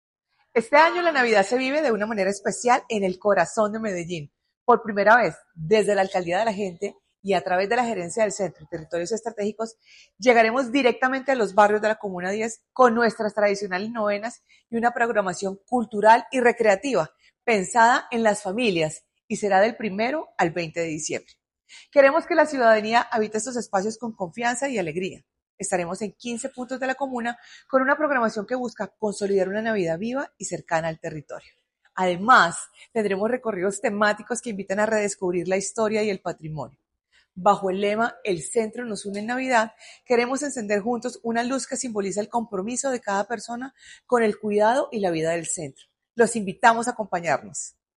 Declaraciones del gerente del Centro y Territorios Estratégicos, Juliana Coral Posada La Alcaldía de Medellín, a través de la Gerencia del Centro y Territorios Estratégicos, llevará este año la celebración navideña a los barrios de la comuna 10 – La Candelaria.
Declaraciones-del-gerente-del-Centro-y-Territorios-Estrategicos-Juliana-Coral-Posada.mp3